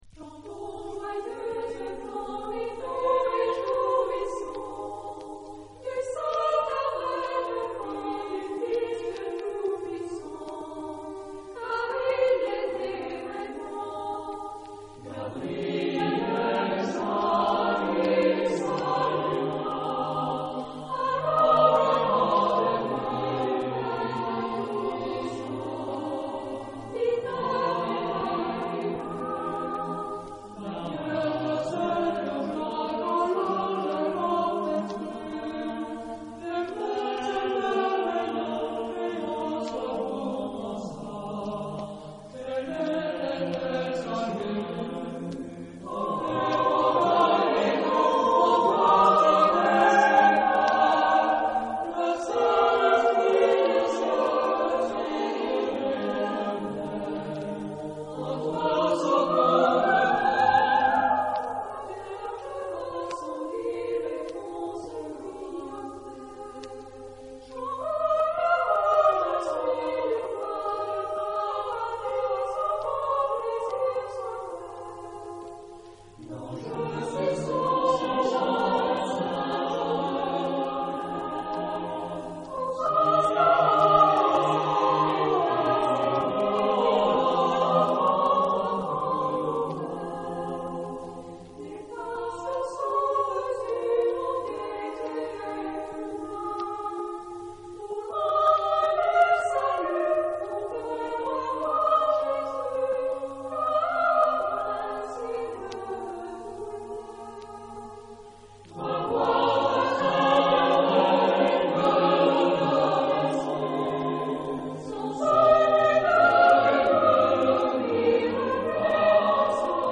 Genre-Style-Forme : Profane ; noël ; contemporain
Type de choeur : SATB  (4 voix mixtes )
Tonalité : sol majeur